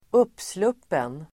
Uttal: [²'up:slup:en]